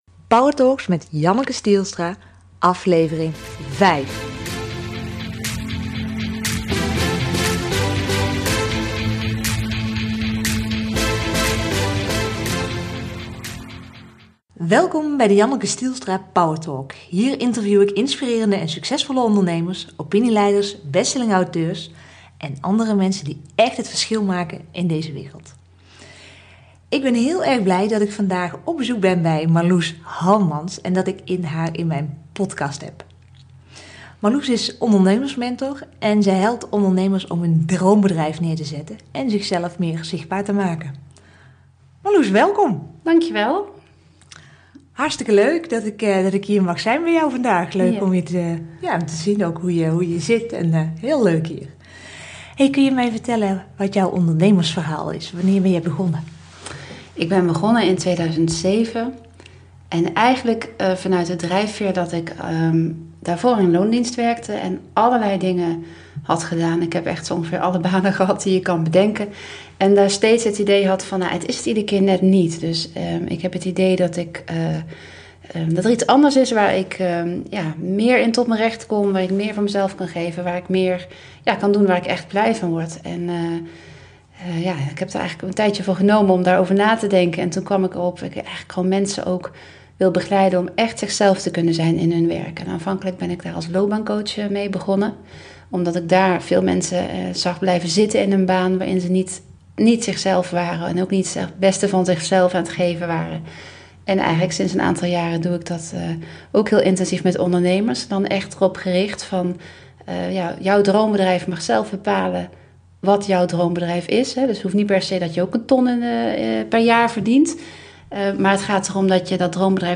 Ik ben benieuwd wat jij uit dit interview als grootste inzicht meeneemt?